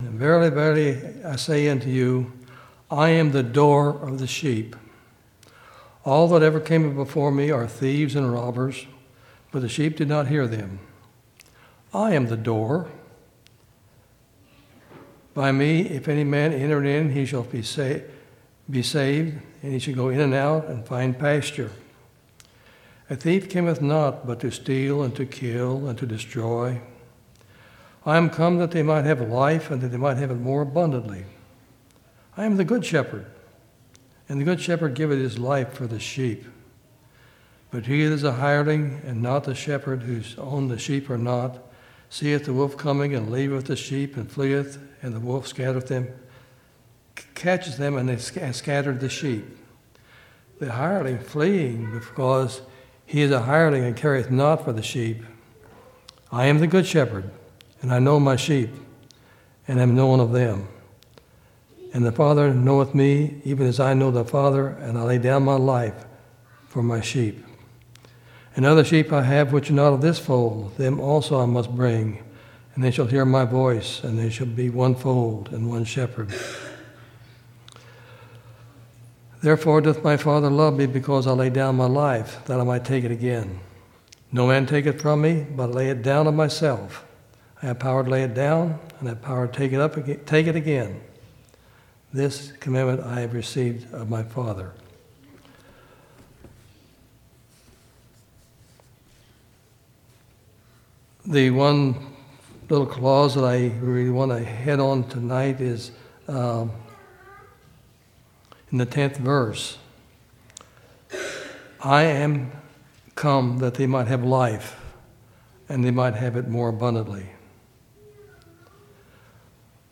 6/2/2019 Location: Temple Lot Local Event